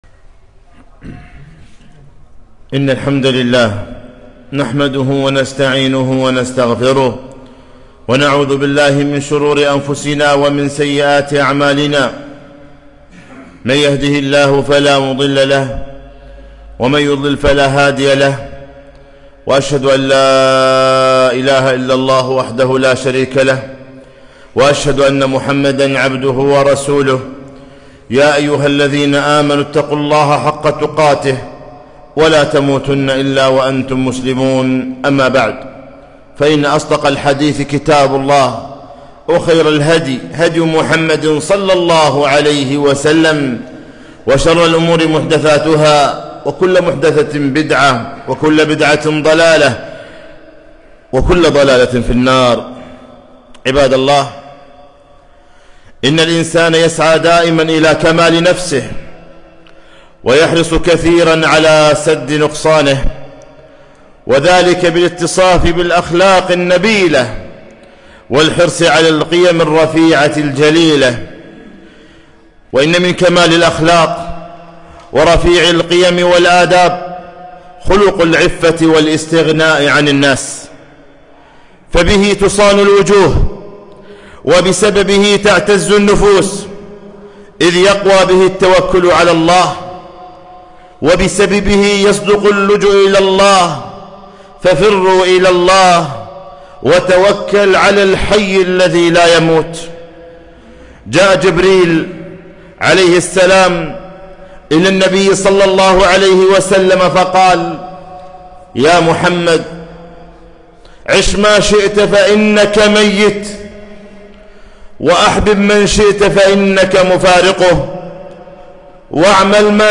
خطبة - العزة في الاستغناء عن الناس